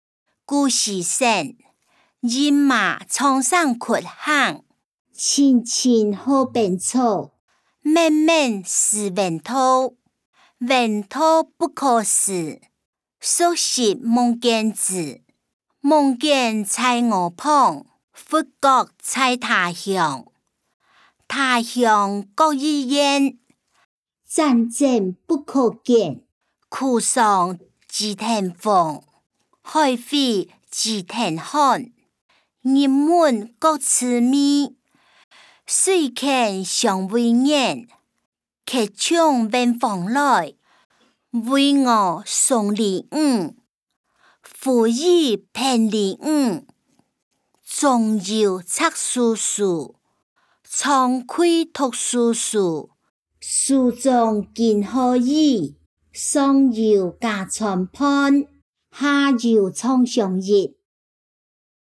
古典詩-飲馬長城窟行音檔(饒平腔)